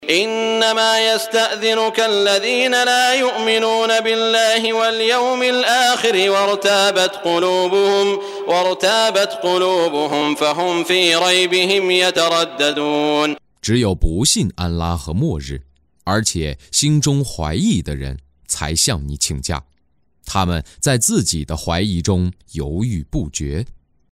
中文语音诵读的《古兰经》第（讨拜）章经文译解（按节分段），并附有诵经家沙特·舒拉伊姆的朗诵